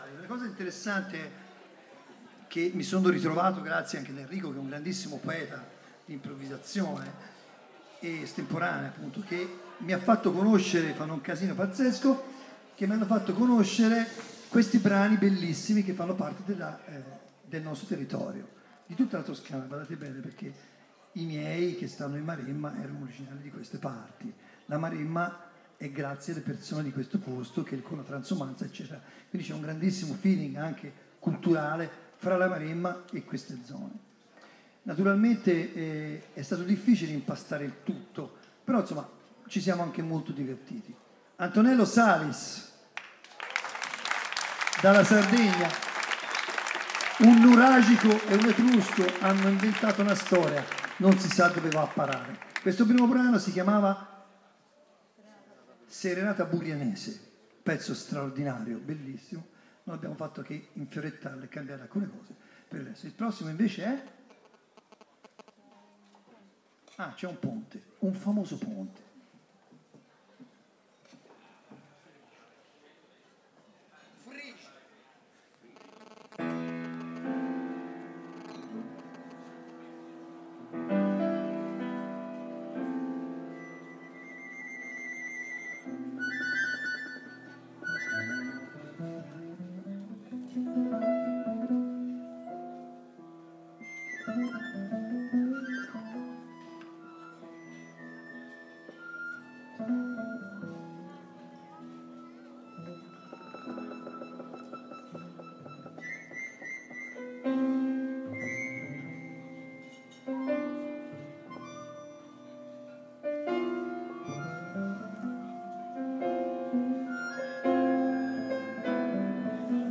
Barga Jazz Festival 2013 - “Sonata di Mare”
fisarmonica e tastiere
batteria
contrabbasso
tromba
sax e flauto